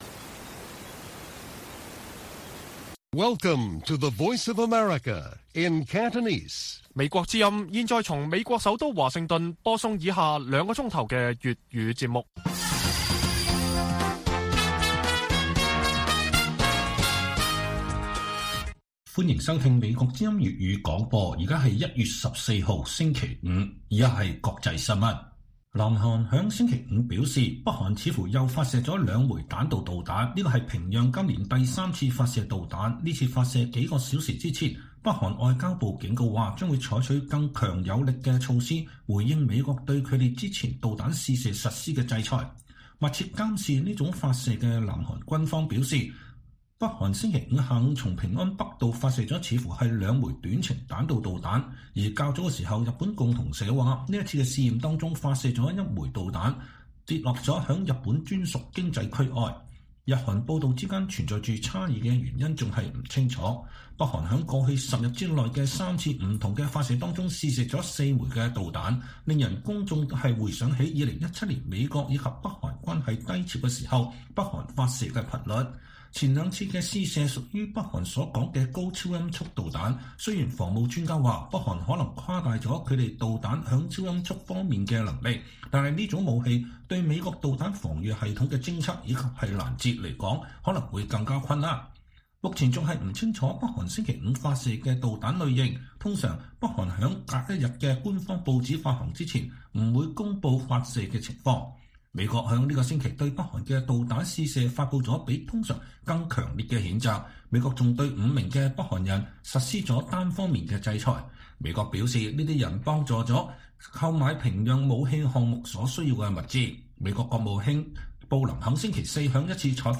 粵語新聞 晚上9-10點: 香港民調顯示3分之2受訪者對防疫措施持負面評價